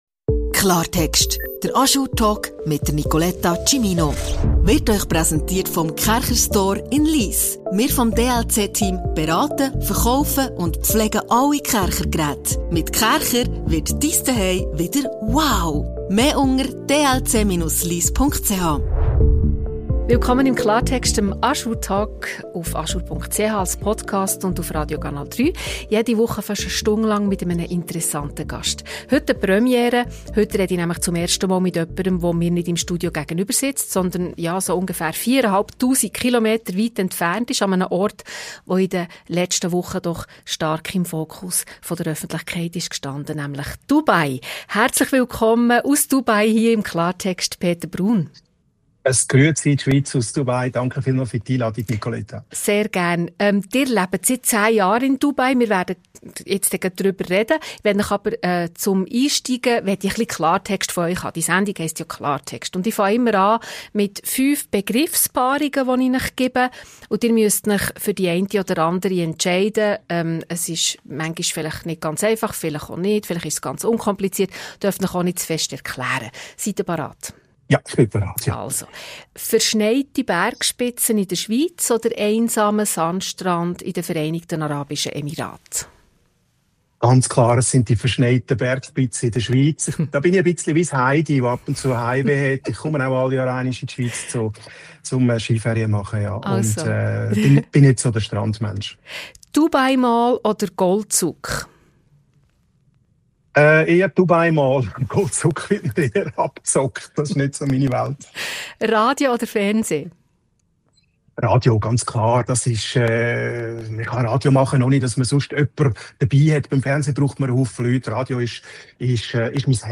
Ein Gespräch über Krisenkommunikation, die Medien, sein Leben zwischen Dubai und der Schweiz - und warum Heimat und Daheim nicht das gleiche ist.